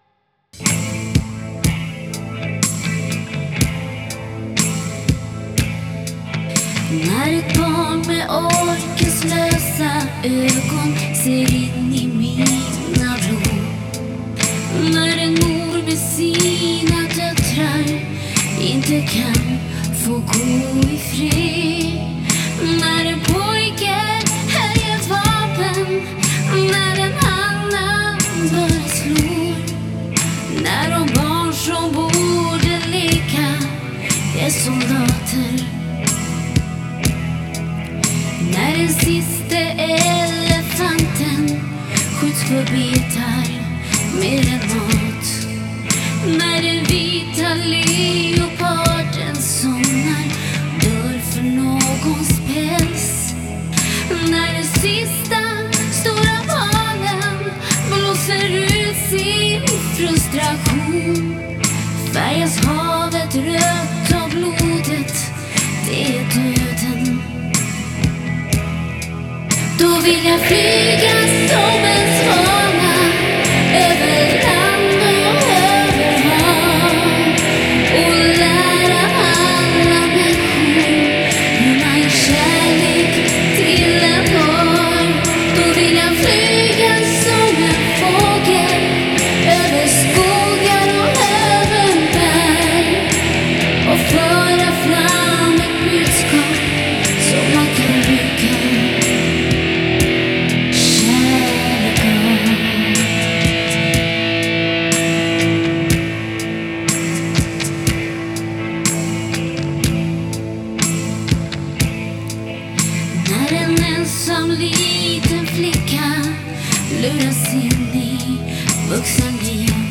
här med Lisa Nilsson på sång.